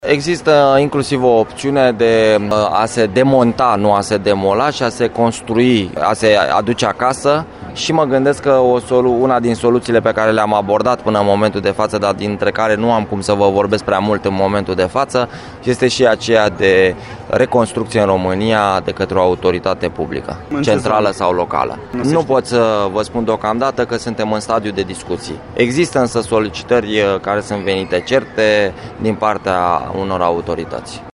Într-un interviu pentru Radio Timișoara, Comisarul general al României la Expo 2015, Georgian Ghervasie, spune că există intenția mutării casei în țară, după 31 octombrie, dată la care se va încheia evenimentul mondial de la Milano și că mai mulți reprezentanți ai administrațiilor locale sunt interesați.